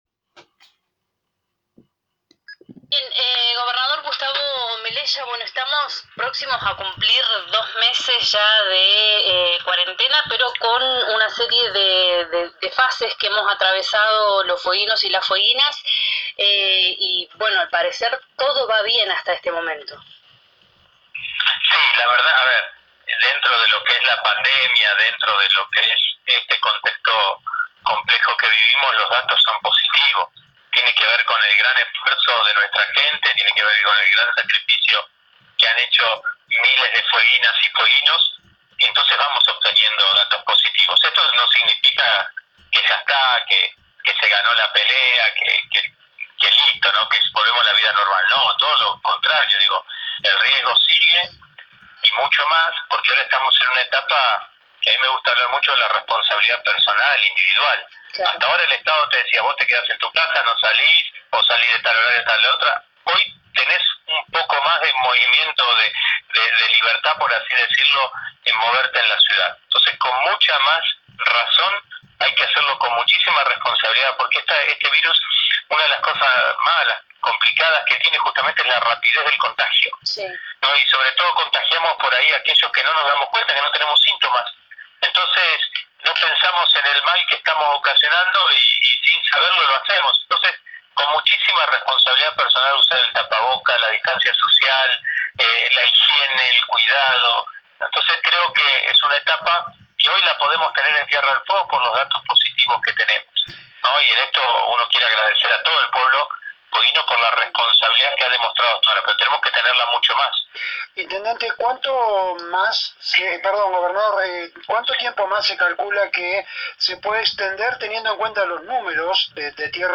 Lun 11/05/2020.- En dialogo con este medio el mandatario fueguino se explayó sobre varios temas, entre ellos la continuidad de la cuarentena, responsabilidad personal de los ciudadanos, la situación económica y financiera, la critica a YPF por perdidas millonarias para la provincia, el acuerdo sobre soberanía en Malvinas, recorte de coparticipación y pago de deuda a los municipios